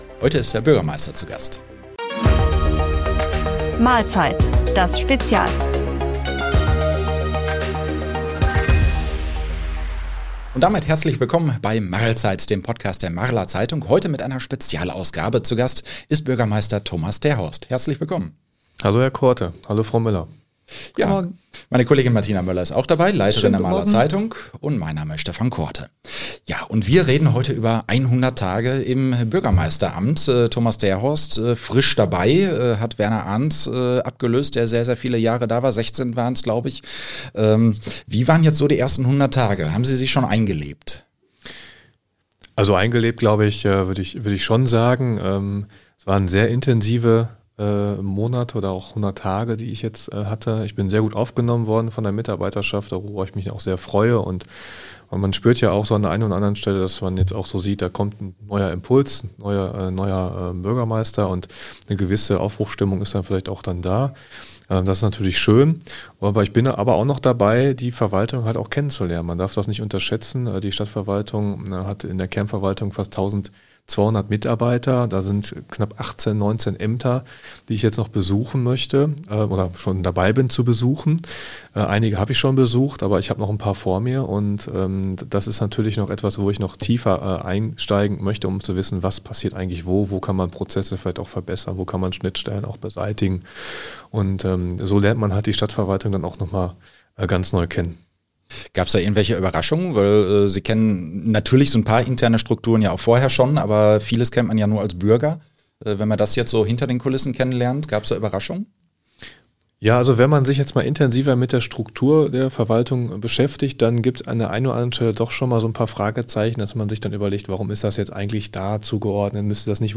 Marls Bürgermeister Thomas Terhorst ist seit 100 Tagen im Amt. Grund genug, unserem Podcast-Studio einen Besuch abzustatten.